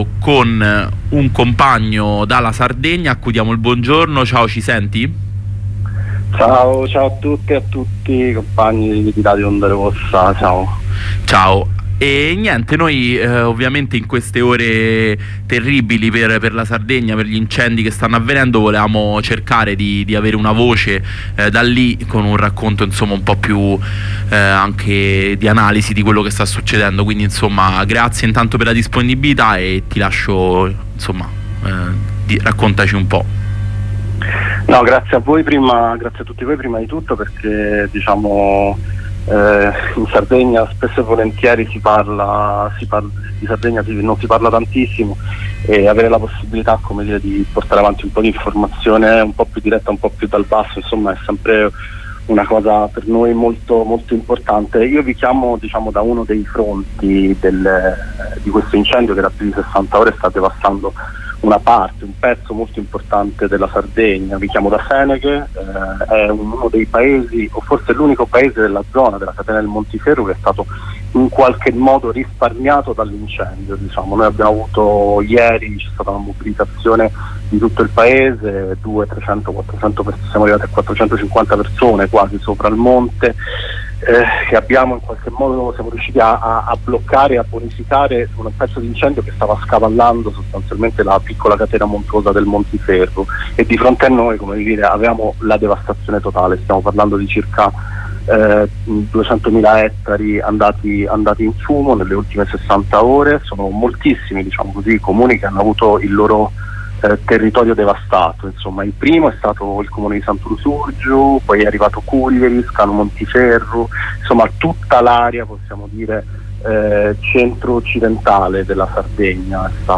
Corrispondenza con un compagno sardo